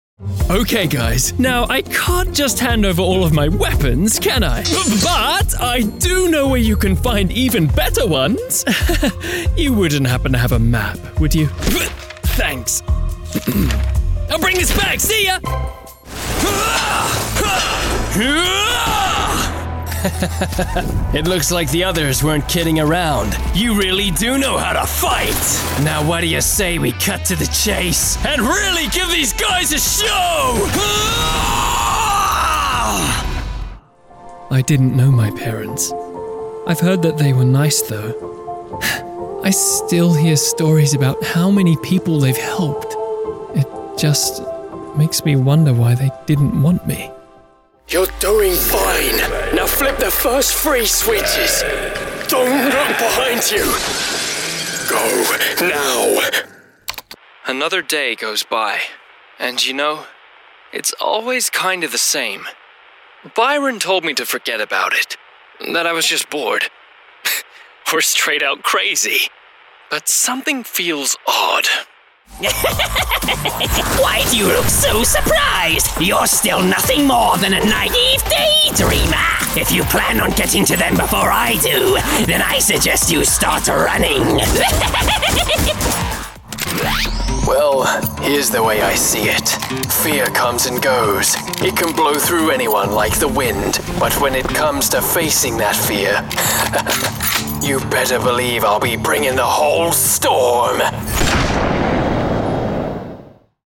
Inglés (Reino Unido)
Videojuegos
- Grabado en una cabina insonorizada
Mi estilo de actuación de voz ha sido descrito como "¡Una voz cautivadora y acogedora con un carácter tremendo!"